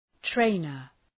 {‘treınər}